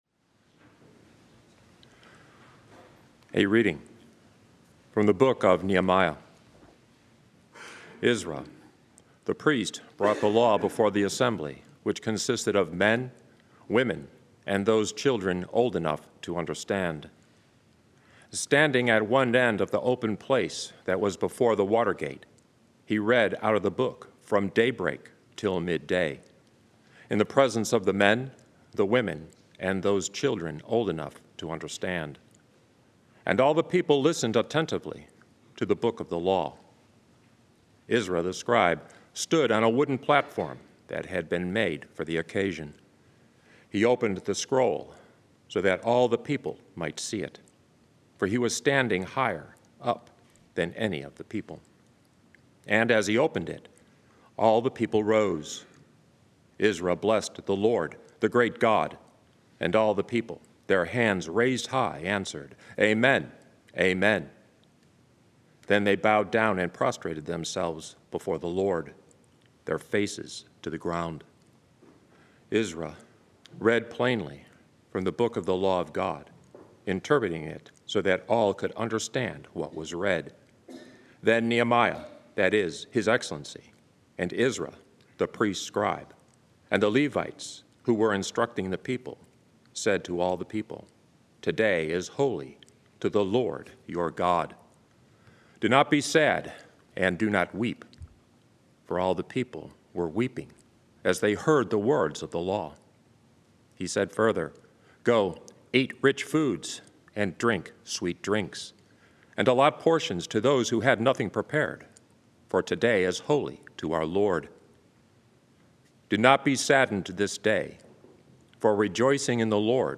Readings, Homily and Daily Mass
From Our Lady of the Angels Chapel on the EWTN campus in Irondale, Alabama.